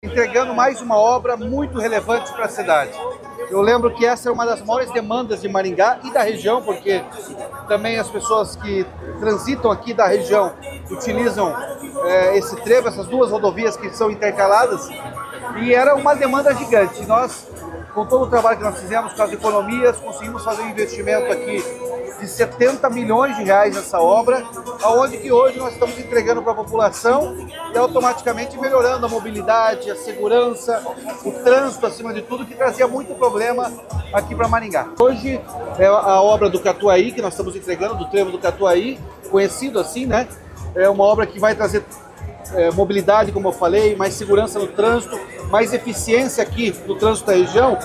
Sonora do governador Ratinho Junior sobre a inauguração do novo Trevo do Catuaí, em Maringá